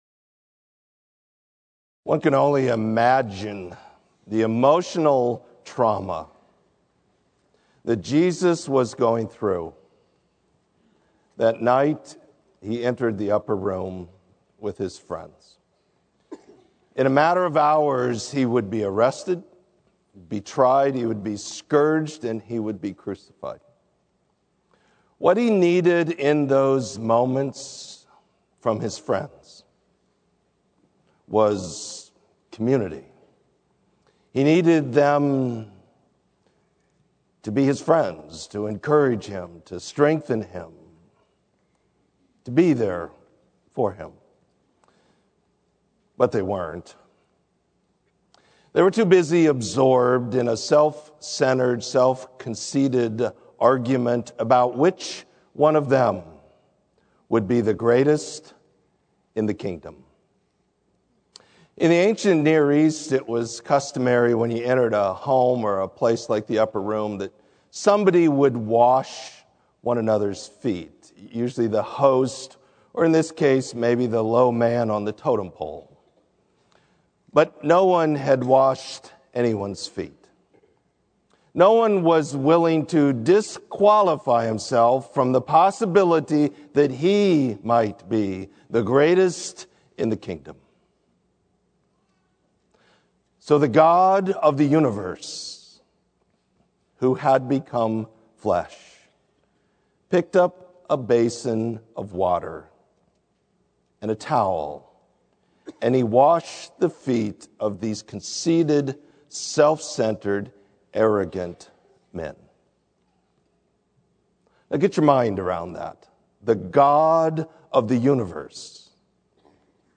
Sermon: Me or You?